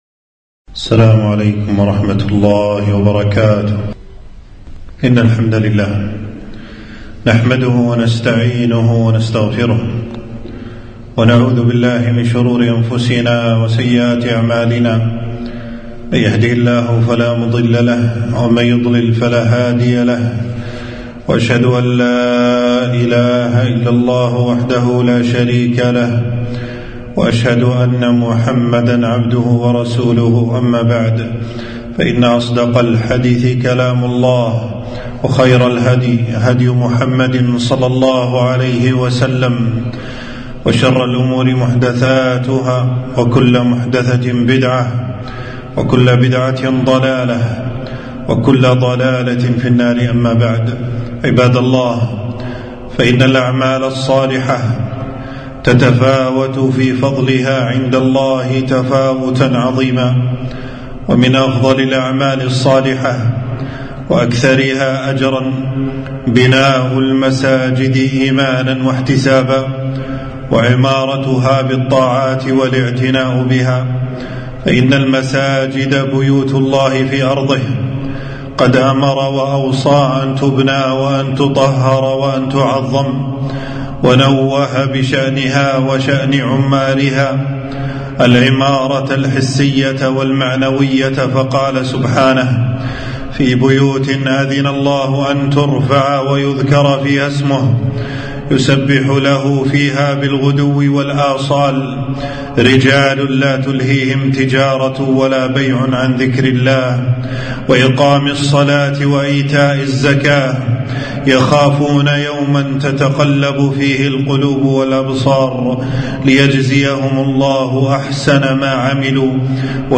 خطبة - بناء المساجد وعمارتها والاعتناء بها